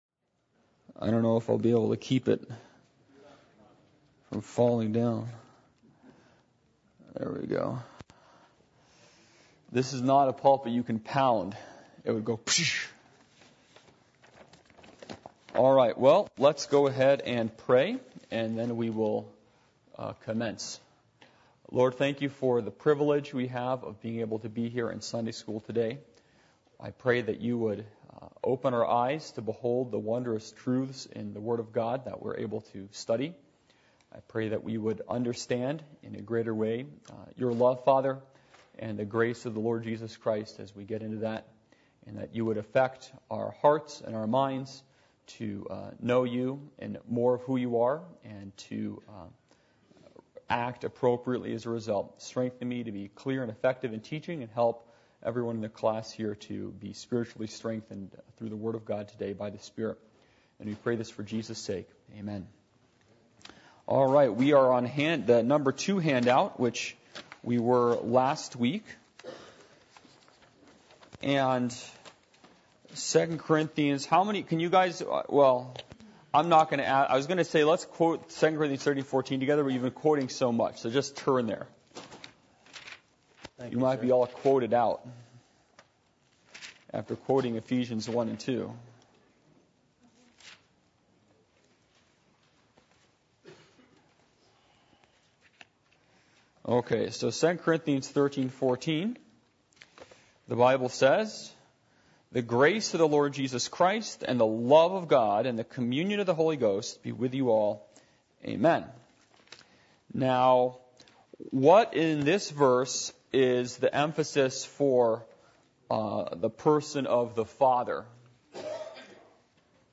Studies on the Trinity Service Type: Adult Sunday School %todo_render% « Fear